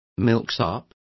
Complete with pronunciation of the translation of milksops.